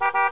BEEP_09.WAV